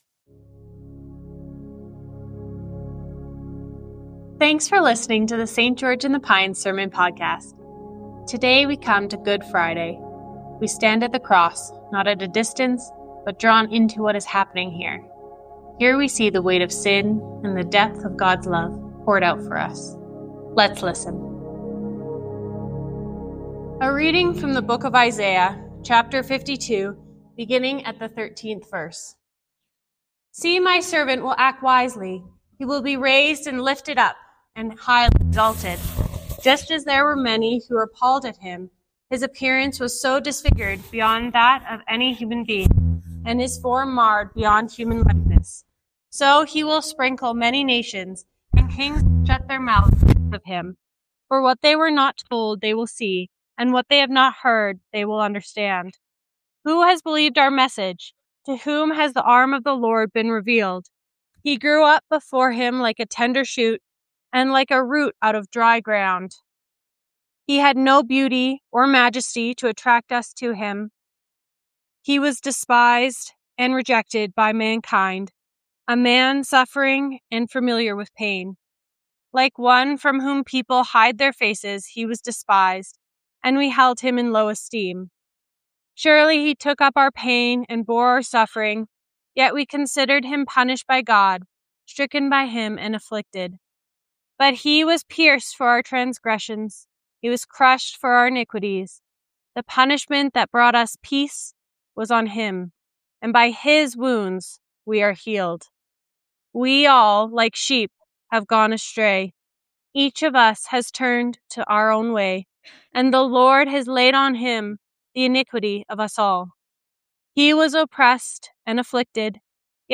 Sermons | St. George in the Pines Anglican Church